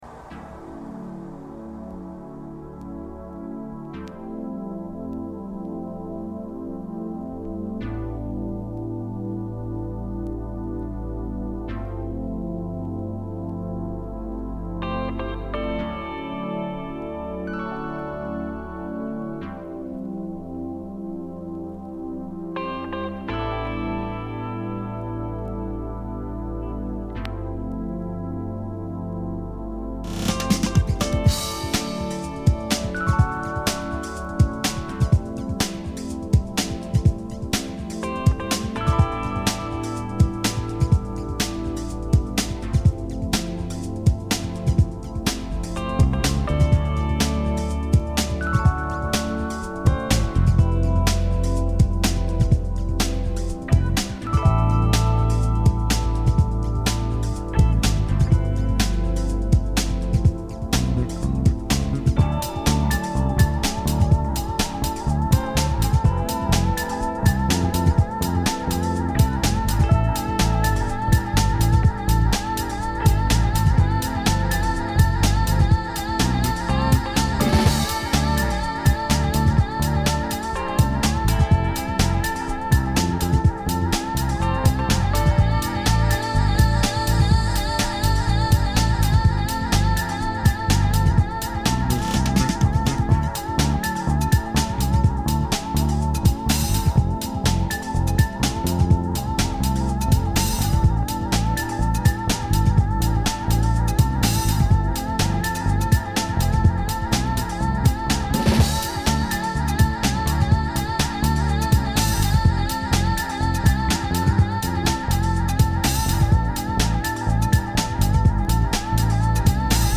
Smooth Grooves before Turkish GP